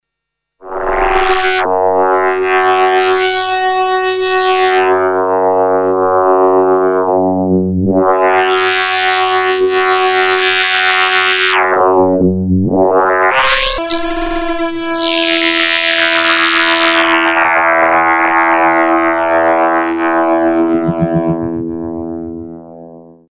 Tweaking Cutoff, Emphasis and Audio-In (the Minimoog trick), played from keyboard.